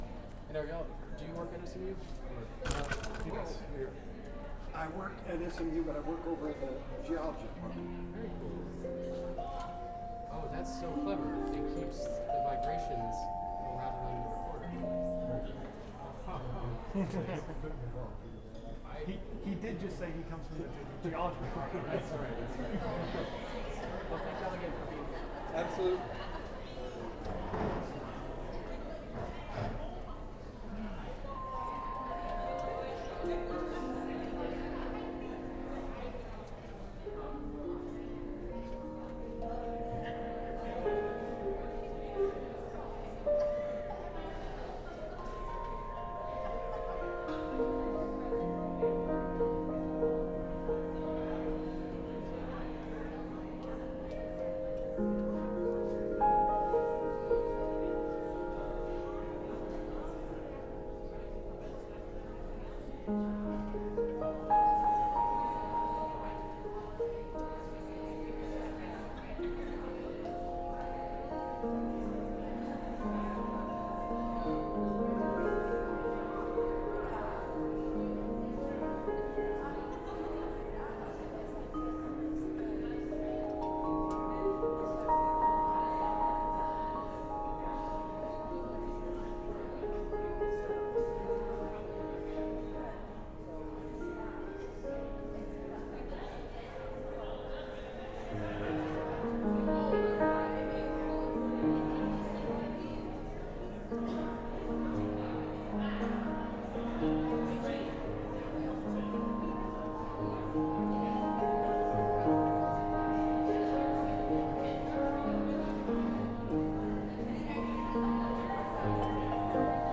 Improvisations
vibes
piano